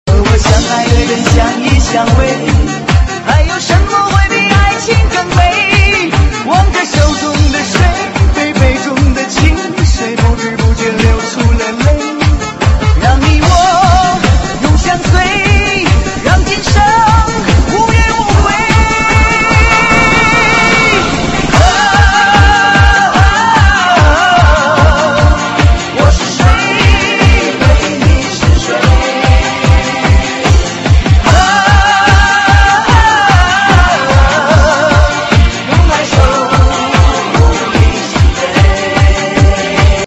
DJ舞曲